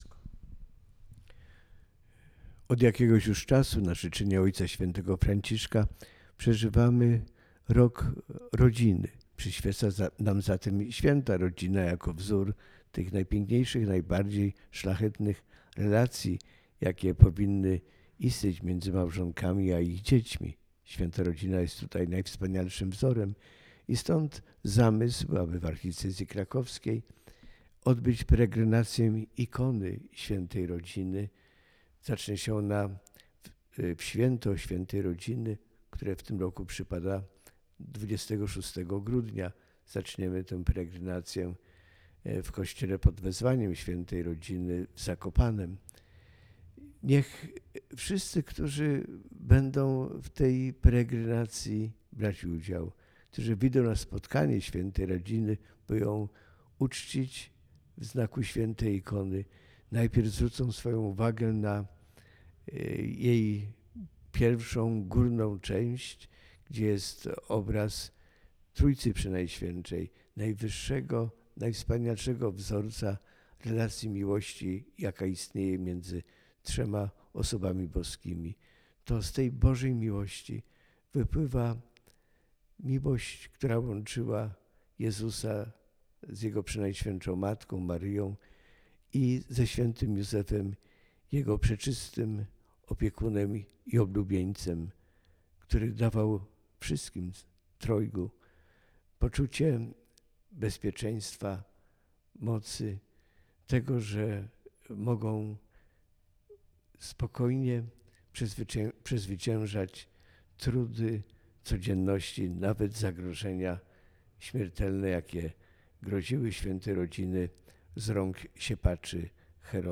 Wypowiedź-Arcybiskupa-marka-Jędraszewskiego-Peregrynacja-Ikony.wav